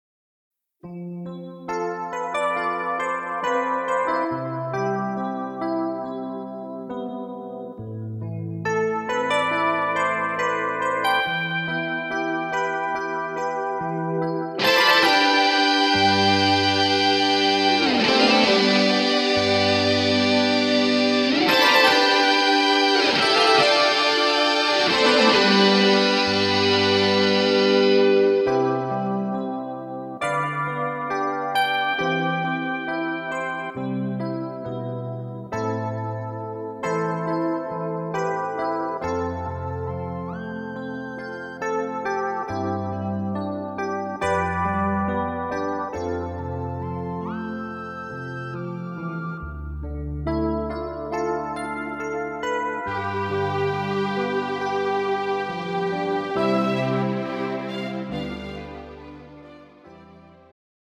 음정 C Key
장르 pop 구분